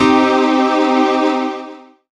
37k02pad1-c.wav